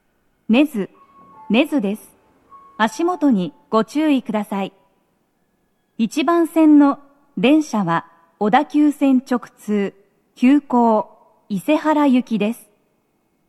足元注意喚起放送が付帯されており、粘りが必要です。
到着放送1